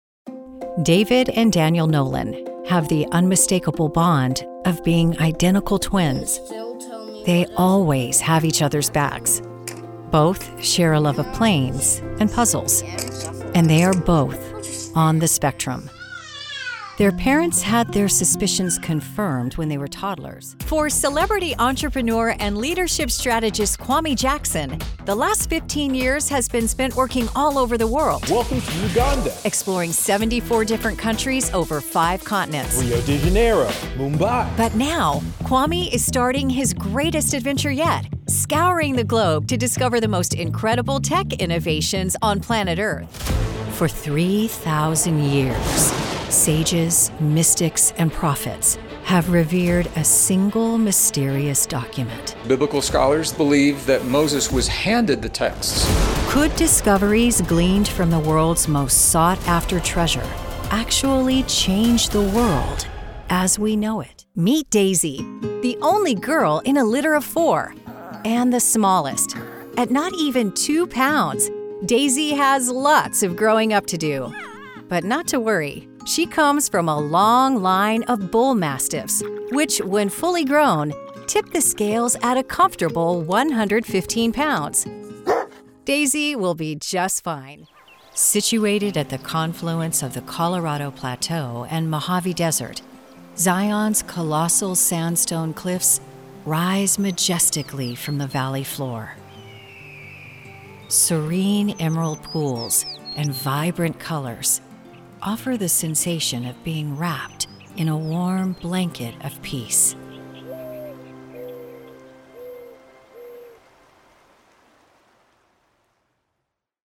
My voice is smooth, warm and friendly.
Documentary In-Show Narration Demo
Southern